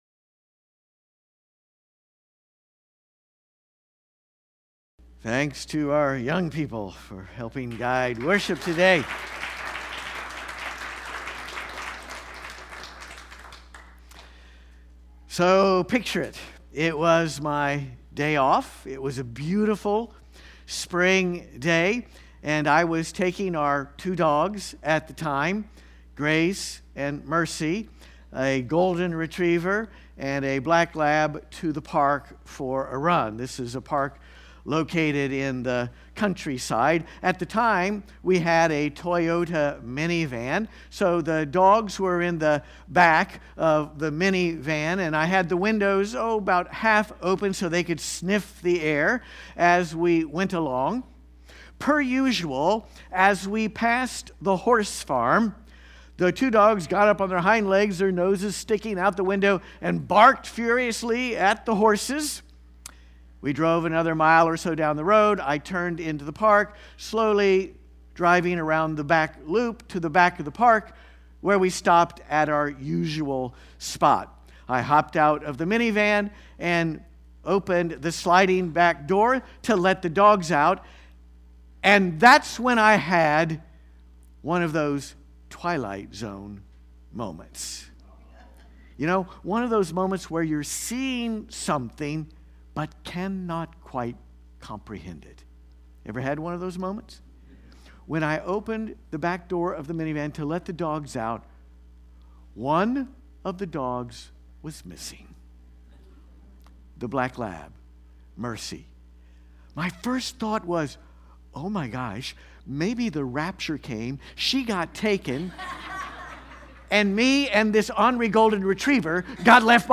Watch the entire Worship Service